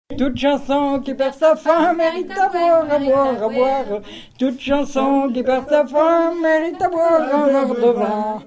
Mémoires et Patrimoines vivants - RaddO est une base de données d'archives iconographiques et sonores.
circonstance : bachique
Pièce musicale inédite